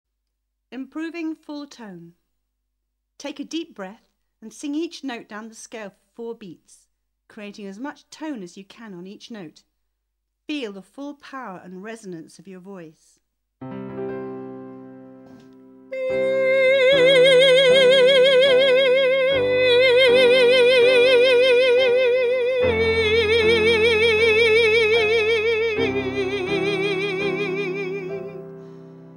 The 'Know Your Voice' CD is a clear and easy to follow explanation of singing technique with demonstrations and exercises for each aspect of producing the voice.